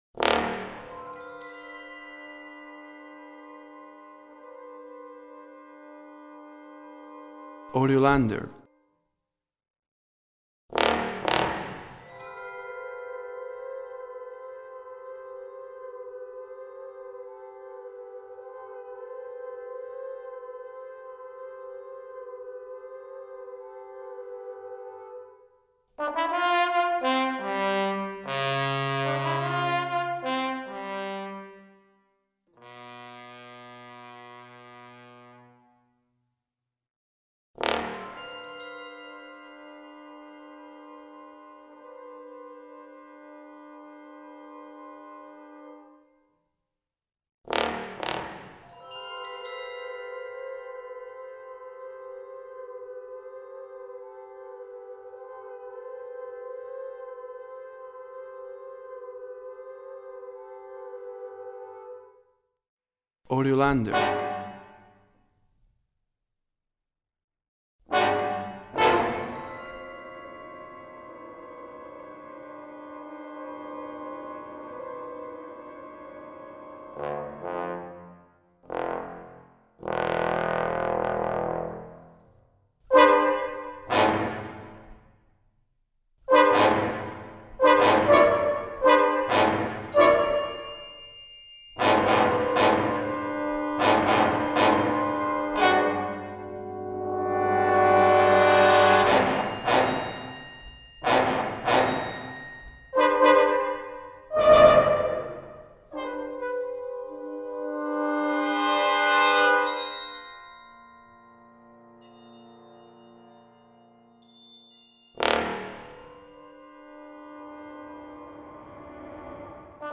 WAV Sample Rate 24-Bit Stereo, 44.1 kHz